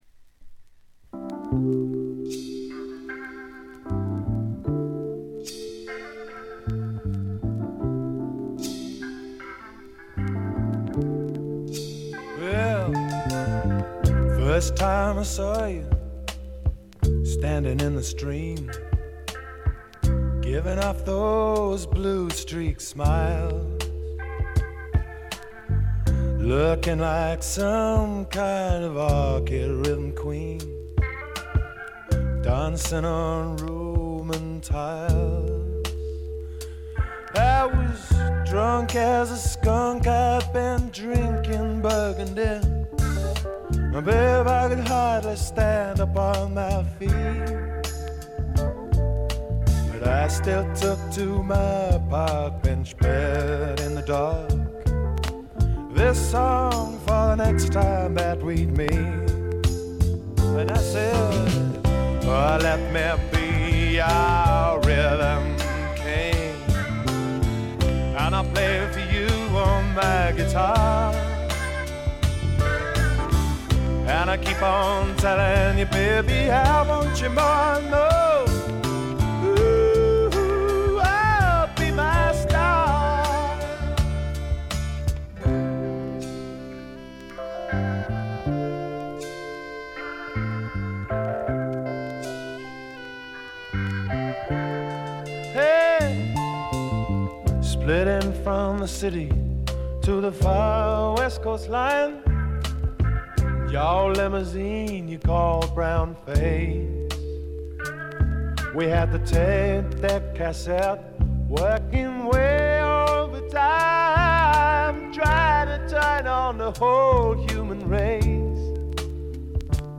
バックグラウンドノイズに軽微なチリプチ。B5序盤5回ほど周回ぎみのプチ音。
試聴曲は現品からの取り込み音源です。